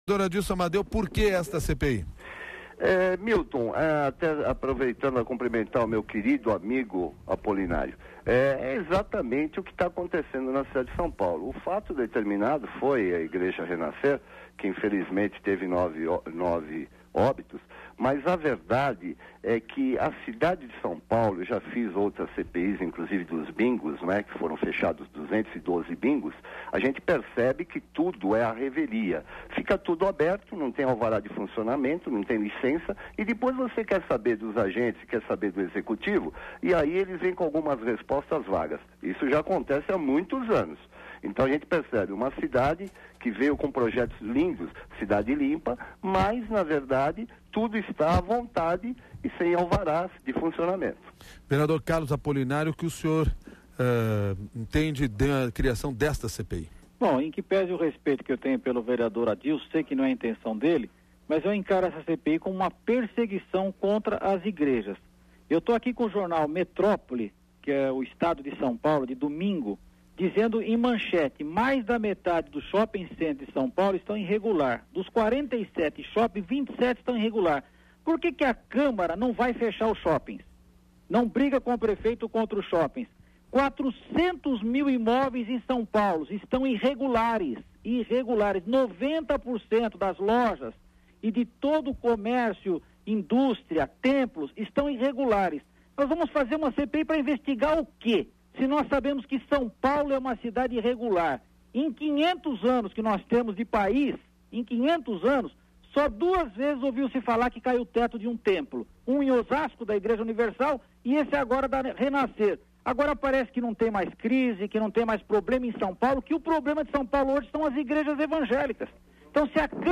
Antecipando-se a discussão que haverá na Câmara Municipal de São Paulo, o CBN SP promoveu, hoje, debate entre os dois vereadores.